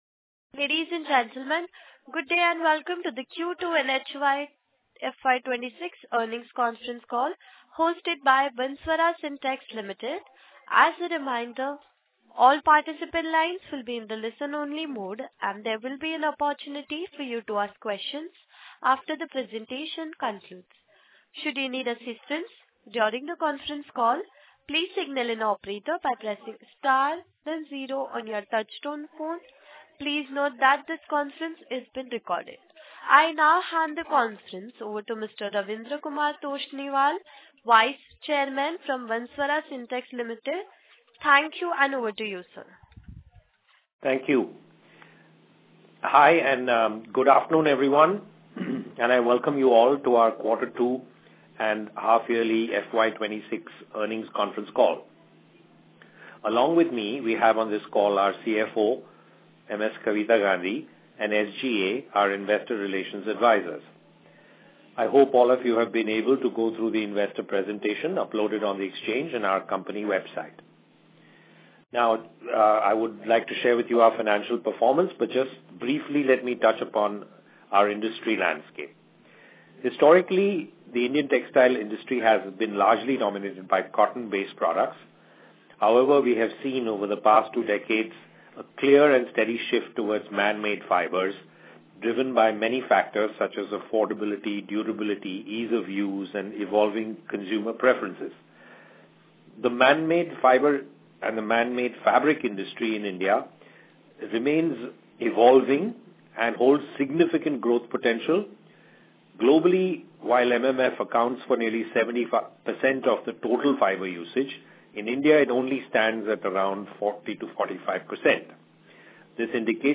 Recording of Earning Conference Call – Q2 FY26